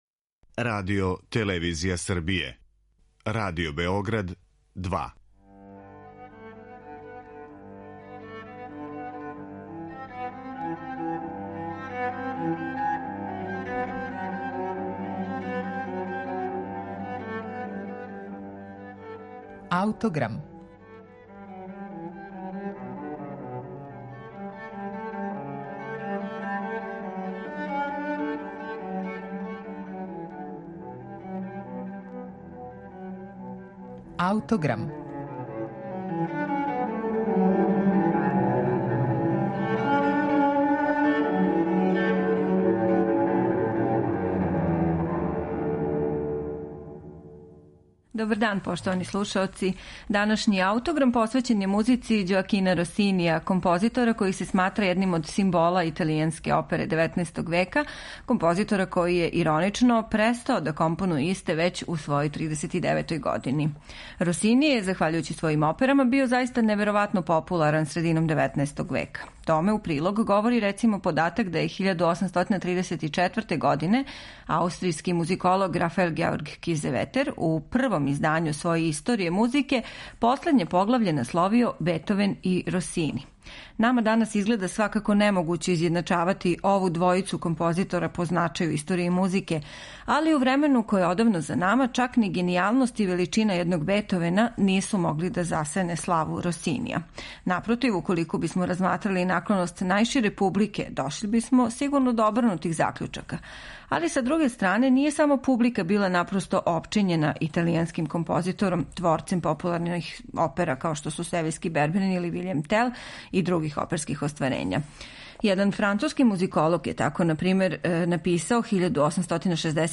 Сонате за гудаче Ђоакина Росинија